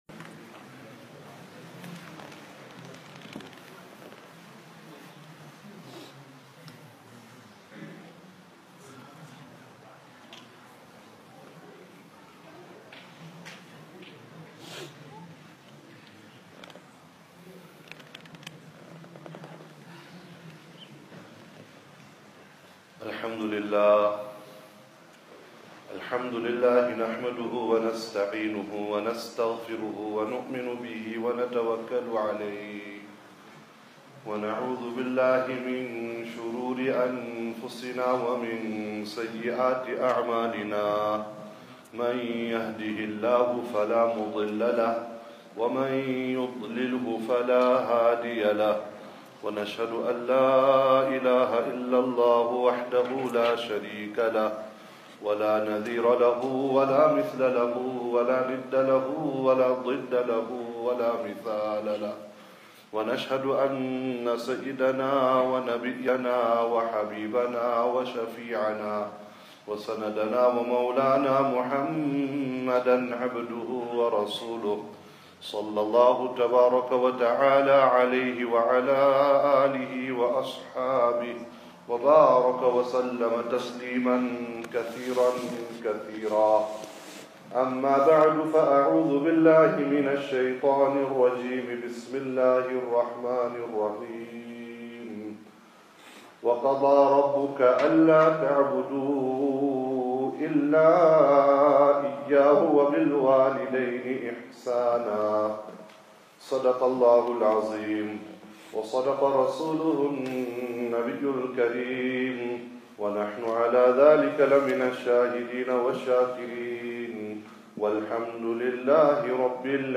Recent Lectures